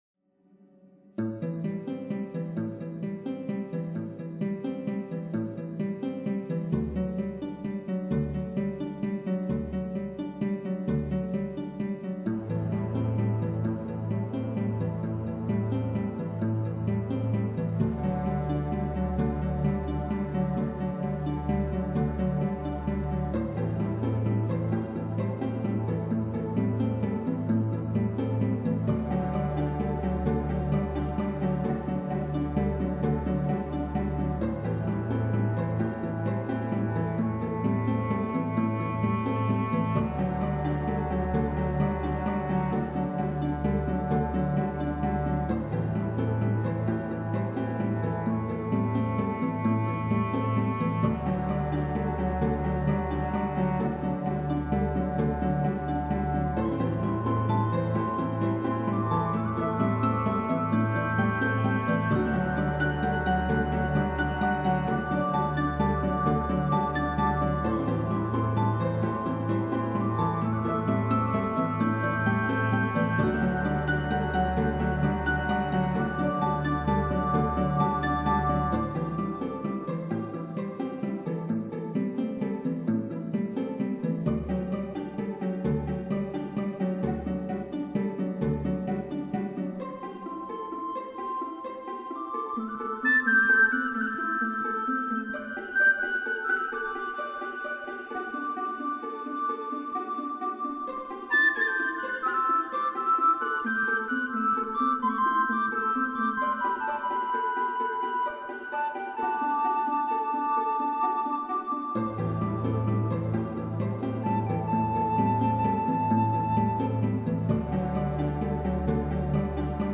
melodic ambient piece